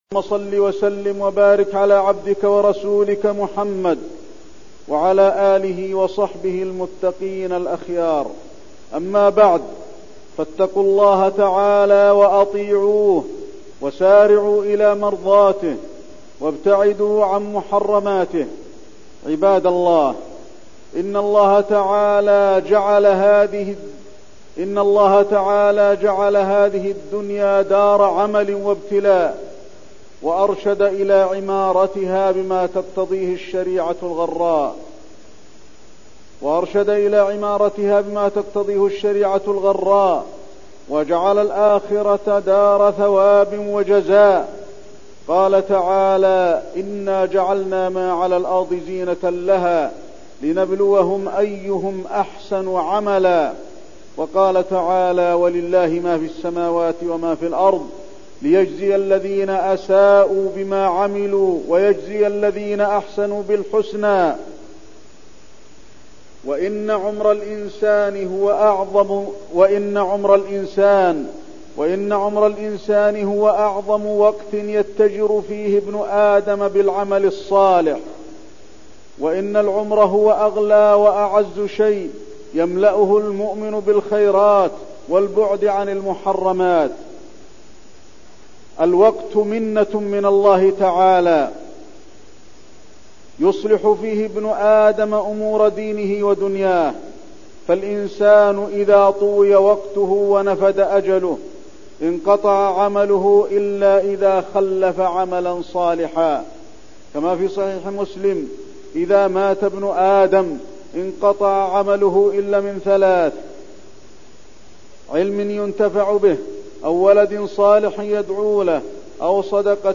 تاريخ النشر ٦ صفر ١٤١٢ هـ المكان: المسجد النبوي الشيخ: فضيلة الشيخ د. علي بن عبدالرحمن الحذيفي فضيلة الشيخ د. علي بن عبدالرحمن الحذيفي أهمية الوقت